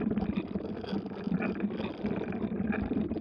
crankingSpinning.wav